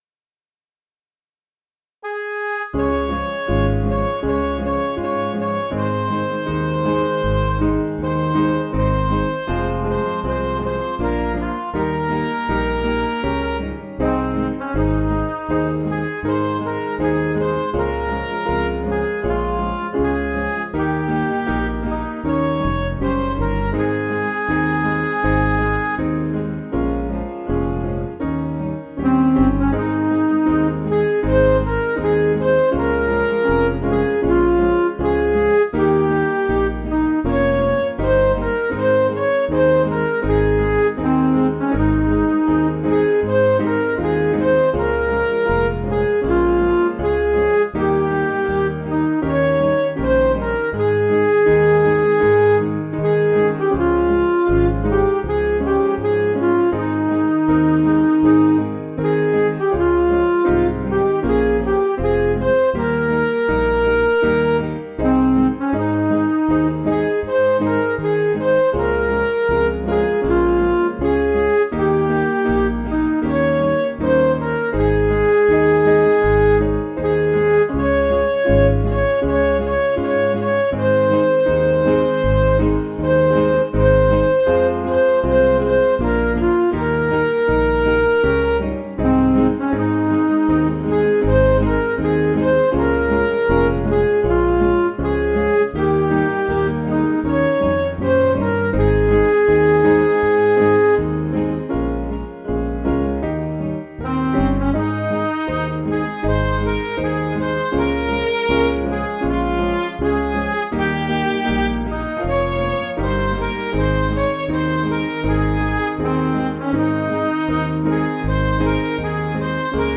Piano & Instrumental
(CM)   3/Ab